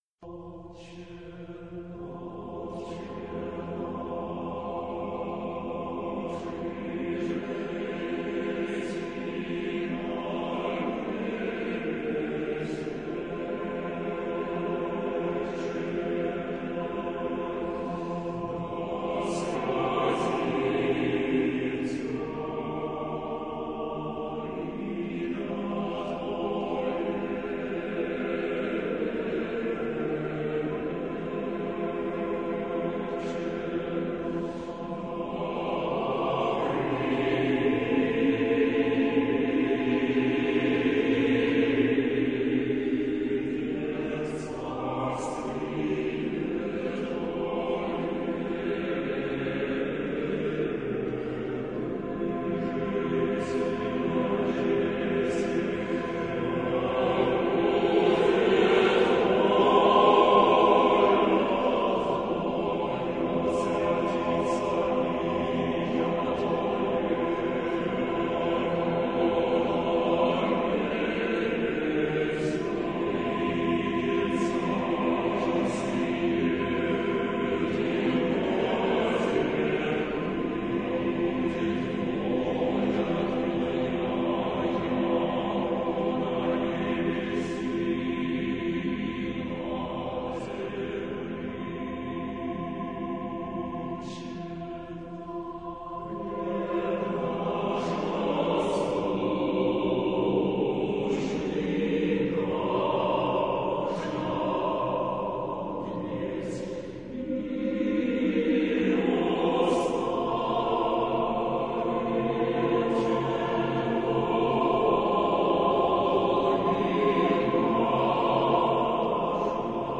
Аудиокнига Искусство умирать. Часть 3 | Библиотека аудиокниг